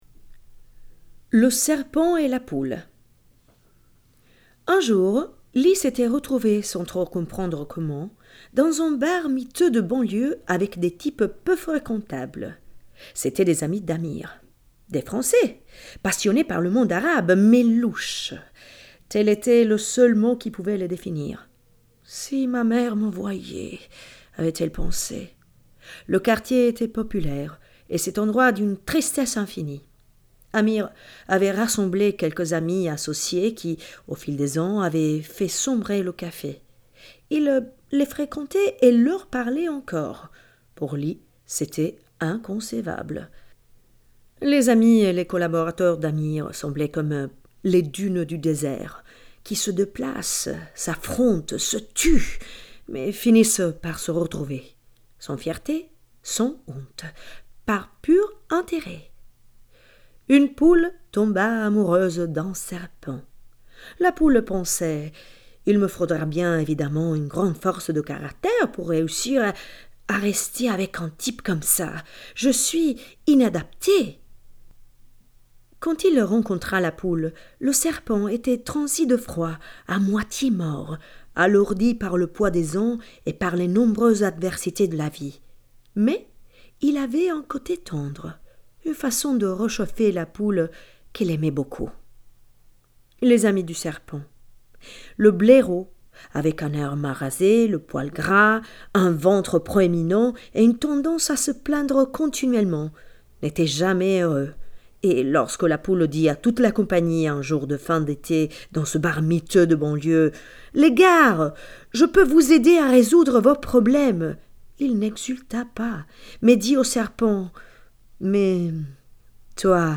“Le Serpent et la Poule”, brano audio tratto dal romanzo L’amante siriano di Rosita Ferrato nella versione francese di recente pubblicazione.